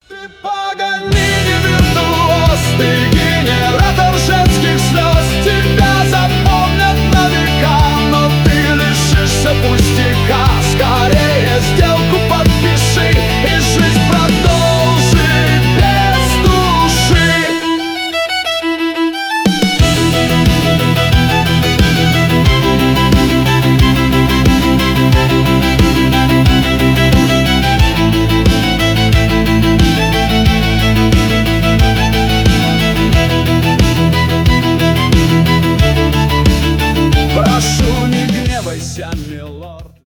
скрипка
рок